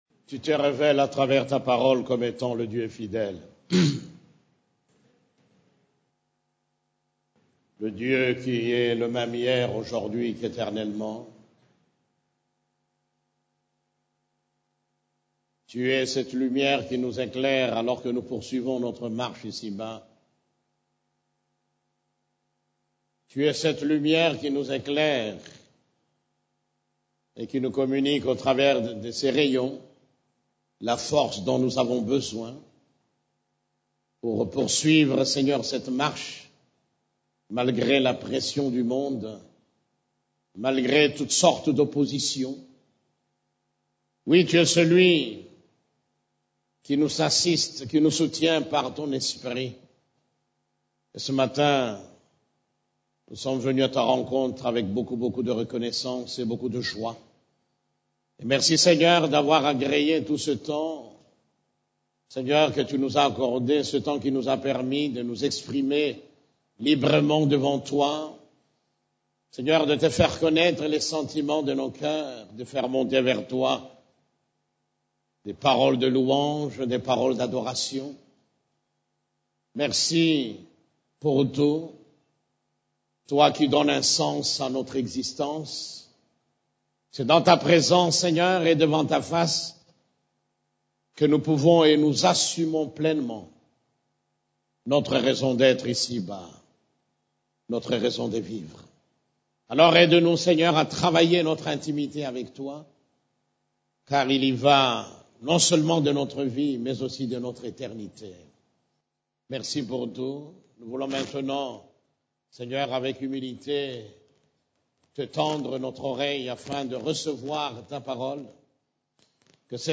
CEF la Borne, Culte du Dimanche, Voir l'invisible afin d'aller loin (7)